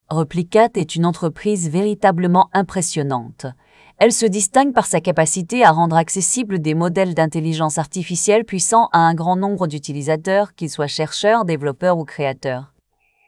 multilingual multilingual-tts text-to-speech voice-cloning
Generate expressive, natural speech in 23 languages. Features instant voice cloning from short audio, emotion control, and seamless cross-language voice transfer.